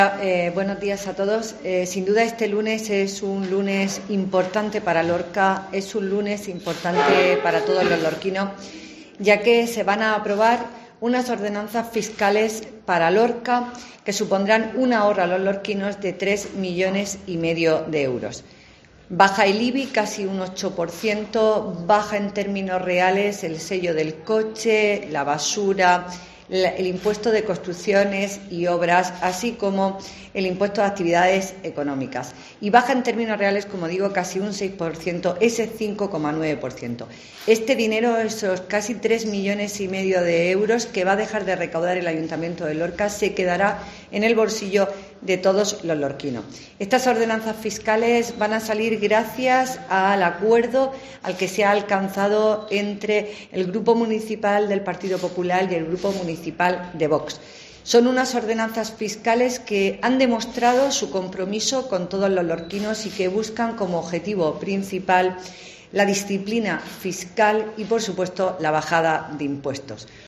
AUDIO: Belén Pérez, edil de Hacienda del Ayto Lorca